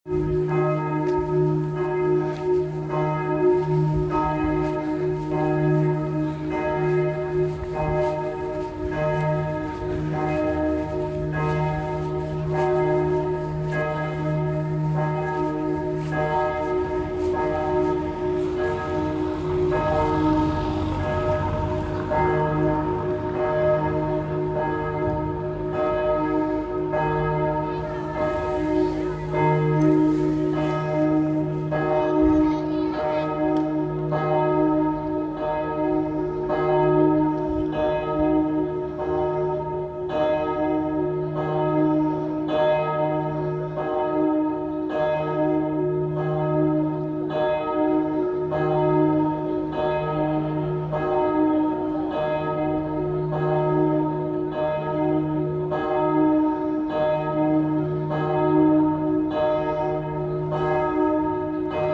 Totenglocke.m4a